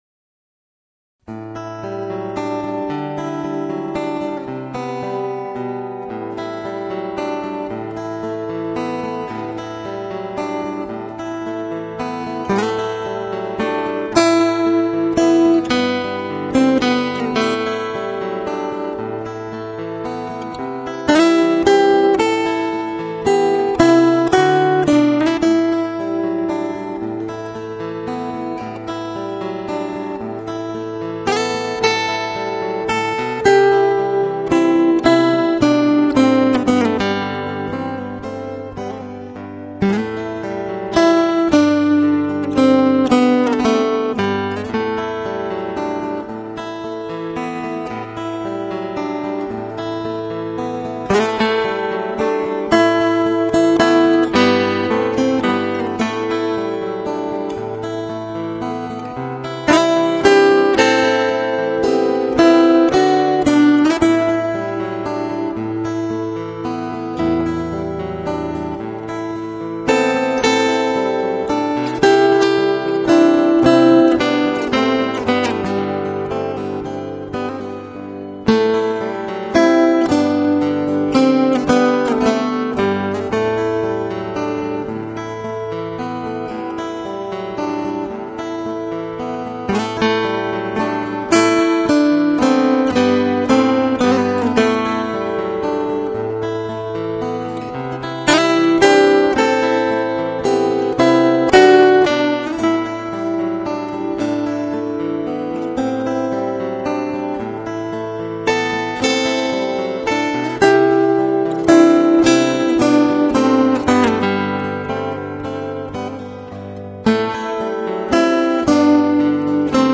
0180-吉他名曲吉斯卡布罗集市.mp3